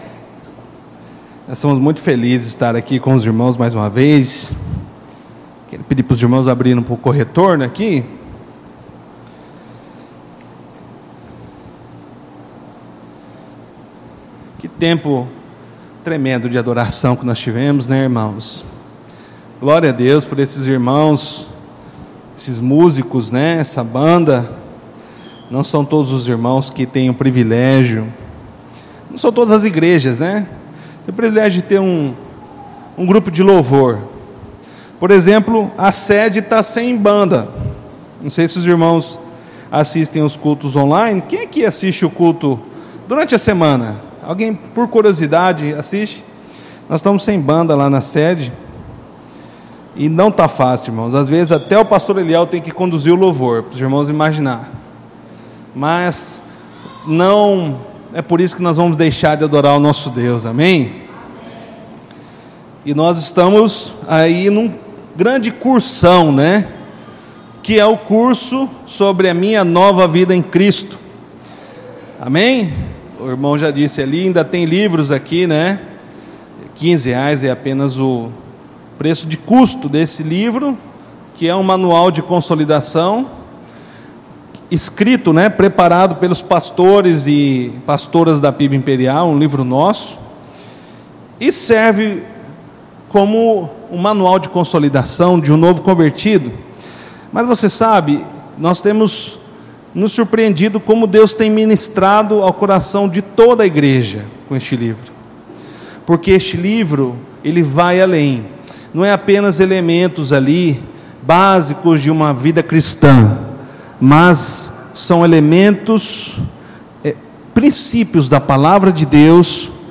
Culto de Celebração